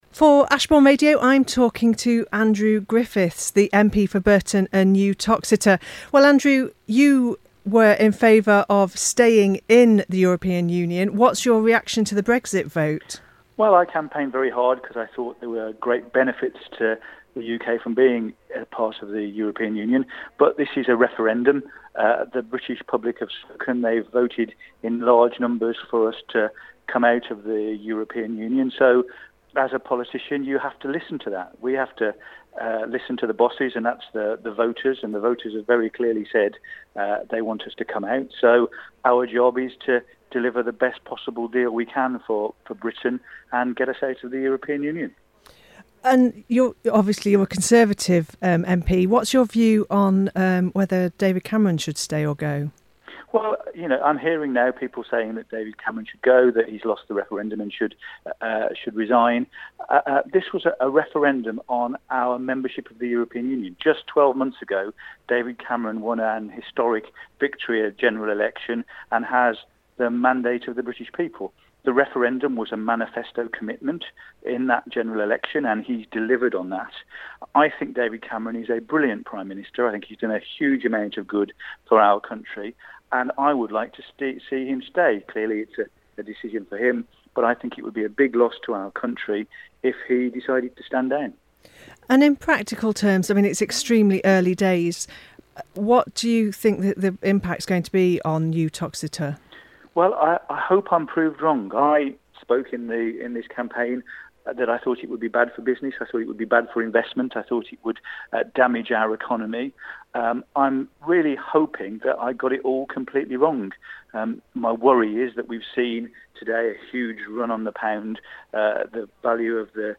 Uttoxeter's MP talks to Ashbourne Radio about Brexit and the PM's resignation. Conservative MP Andrew Griffiths, who campaigned to stay in the EU, told Ashbourne Radio that he hopes that his predictions that Brexit would be bad for business are proved wrong and that the fall in the stock market is only temporary.